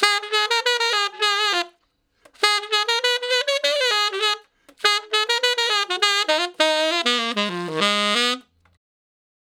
068 Ten Sax Straight (Ab) 12.wav